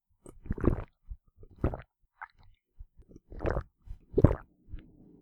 drink.mp3